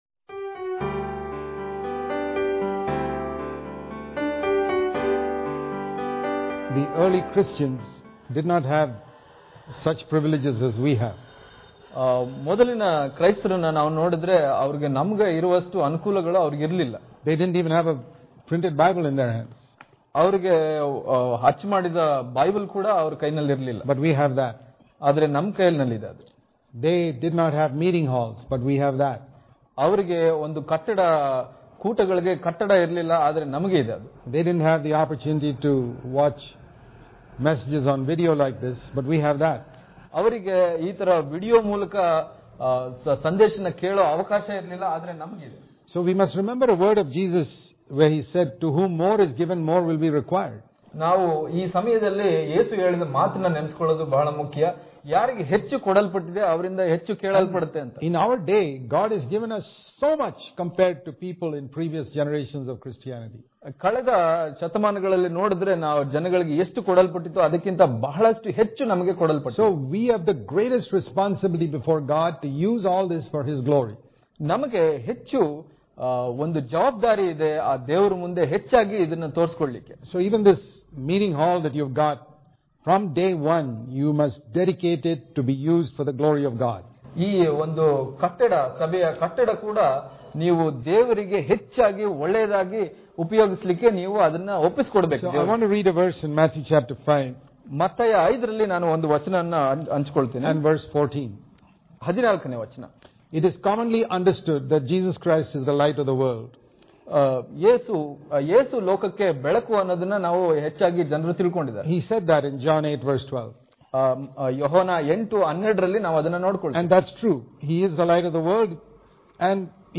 June 14 | Kannada Daily Devotion | The Church Should Be A Bright Light Daily Devotions